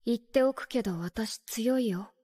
Frieren Voice - ANIMÉDIA